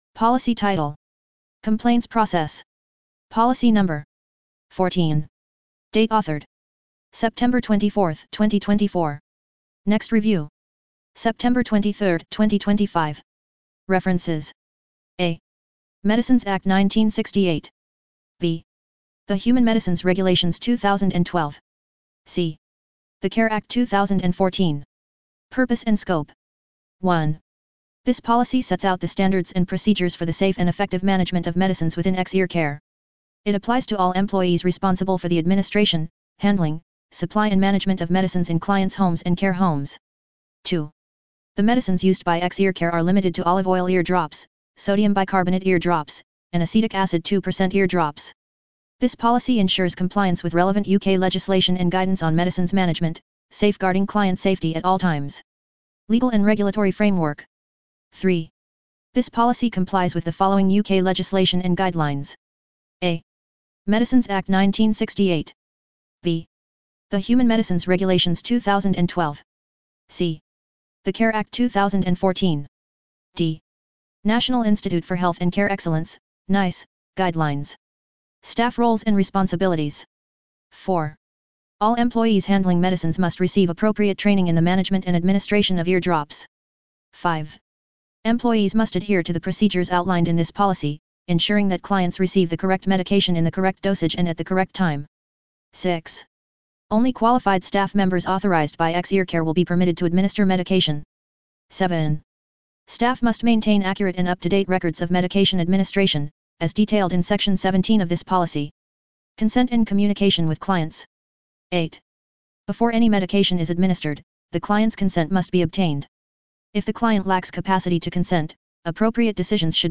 Narrated Medicines Management Policy